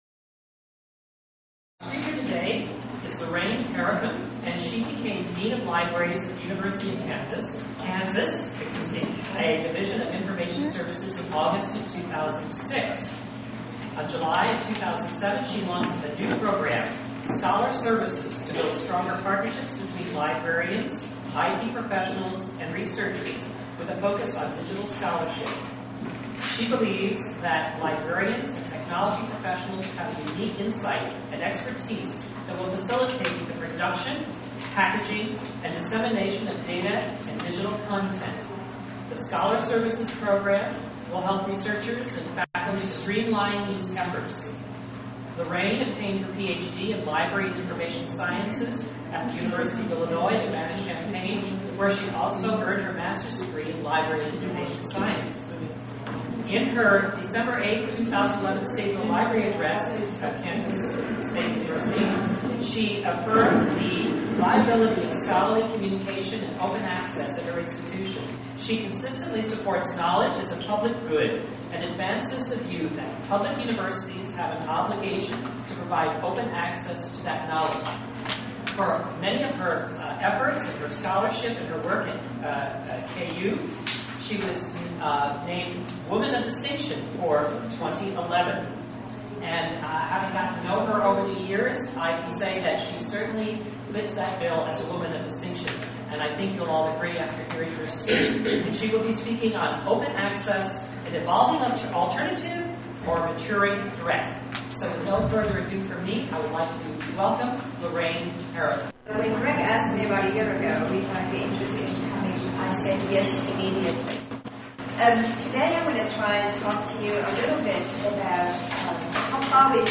Keynote address delivered October 22, 2012 during Open Access Week.